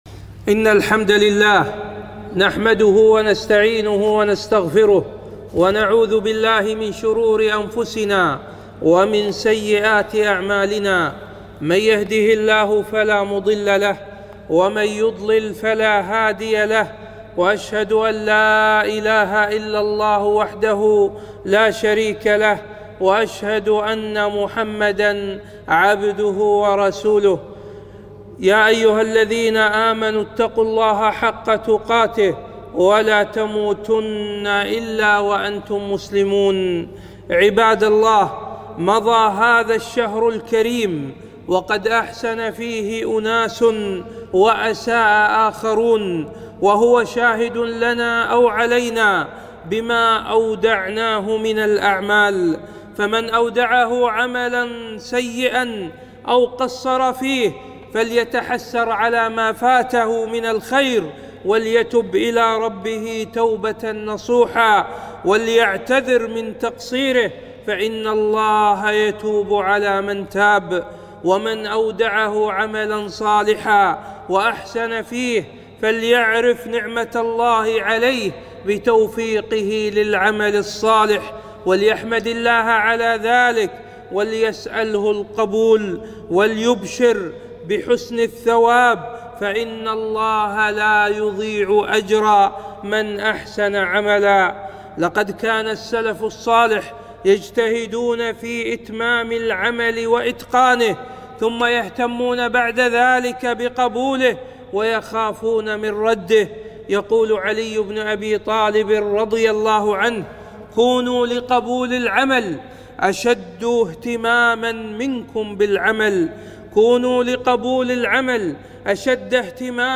خطبة - الثبات على الطاعات